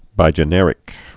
(bījə-nĕrĭk)